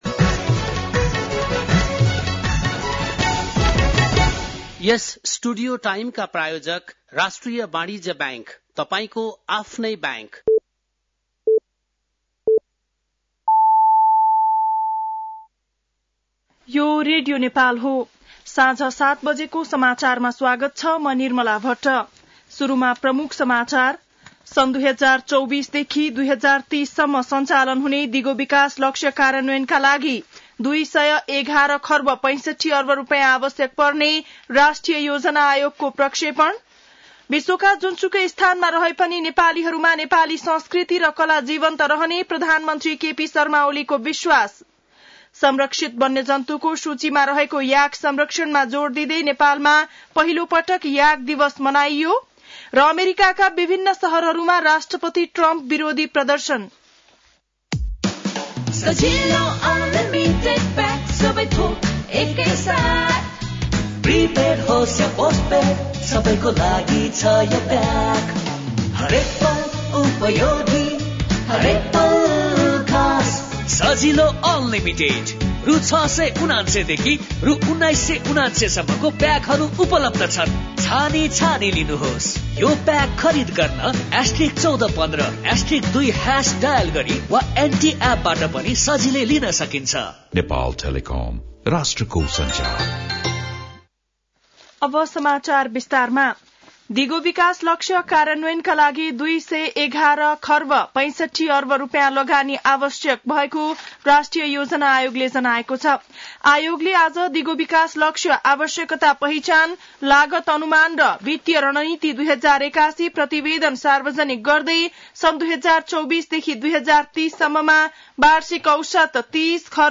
बेलुकी ७ बजेको नेपाली समाचार : ७ वैशाख , २०८२
7-pm-nepali-news1-7.mp3